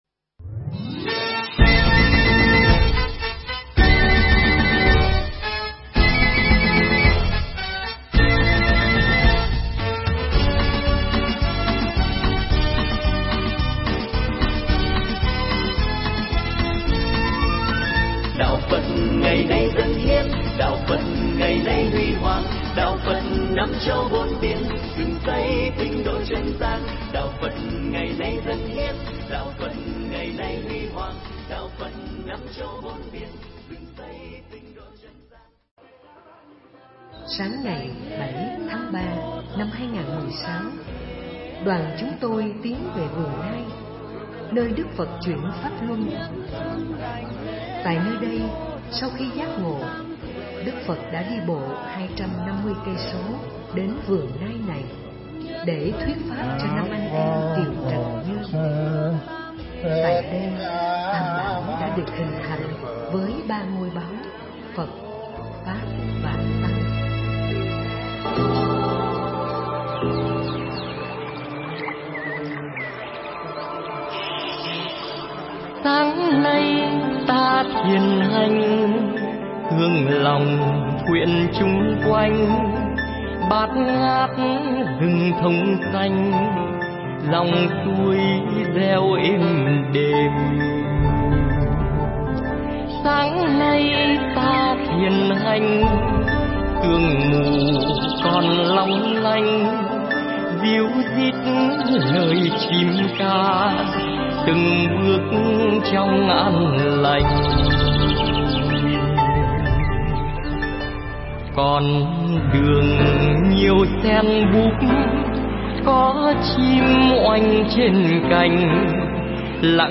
Nghe mp3 Pháp Thoại Hành hương Phật tích Ấn Độ – Nepal tháng 3-2016 – DVD5 - Thầy Thích Nhật Từ tháng 3 năm 2016